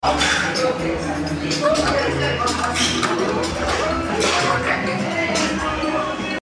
Farting Sound Effects Free Download